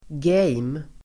Uttal: [ge:m]